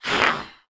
Index of /App/sound/monster/orc_magician
attack_2.wav